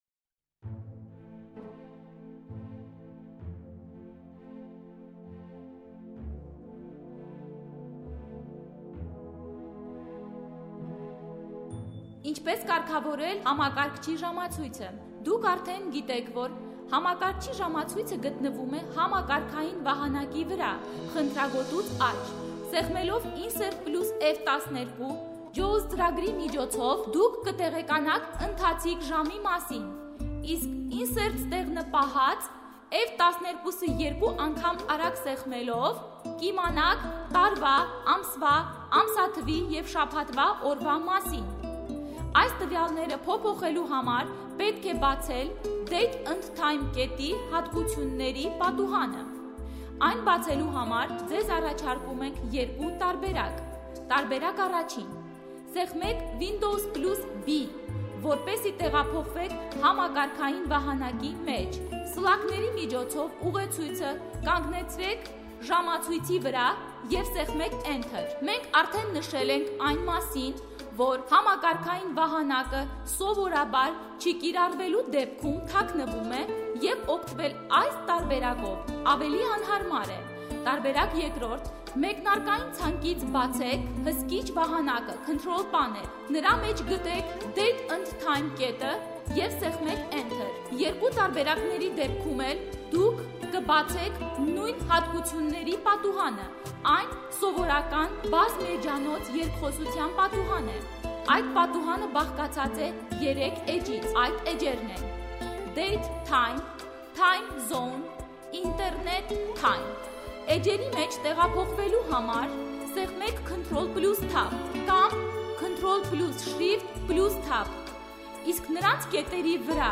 Звуковой учебник для начинающих незрячих пользователей компьютера на армянском языке.